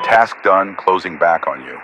Radio-pilotWingmanRejoinTaskComplete2.ogg